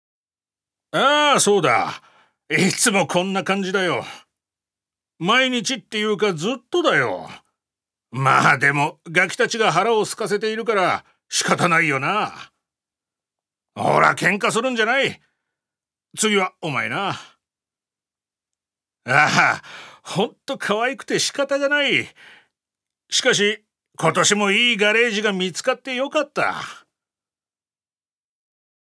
所属：男性タレント
音声サンプル
セリフ４